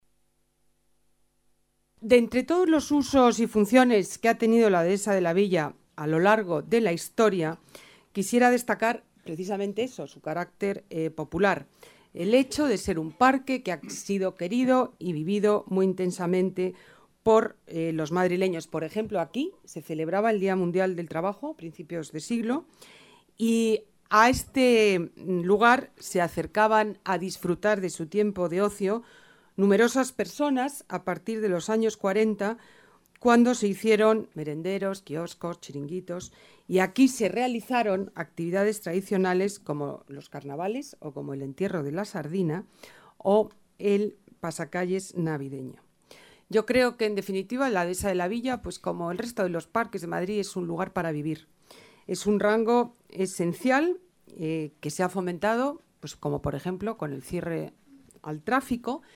Nueva ventana:Declaraciones de Ana Botella, delegada de Medio Ambiente